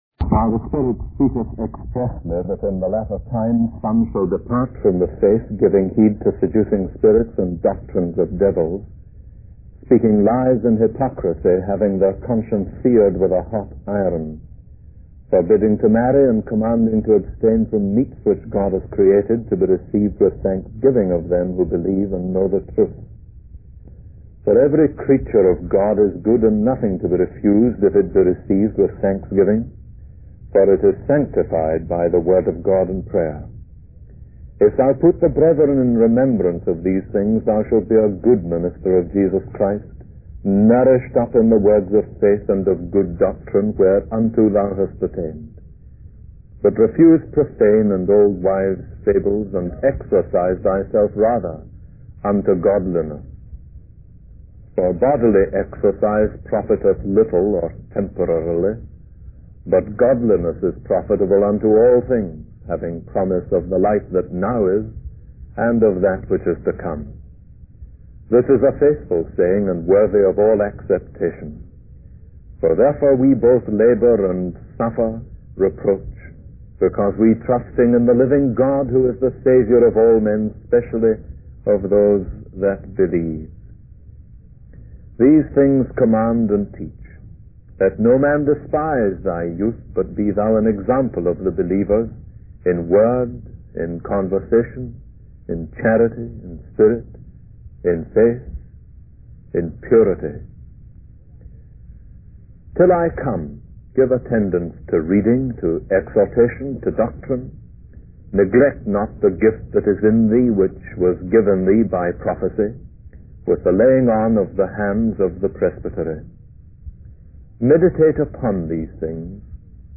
In this sermon, the speaker addresses the importance of the center of gravity in the lives of those who preach the word of God. He emphasizes that the center of gravity should be in the message they preach, and this should be evident to others. The speaker uses the analogy of a river being constrained by its banks to illustrate how the preaching of the word gives depth, dynamic, and direction to the ministry.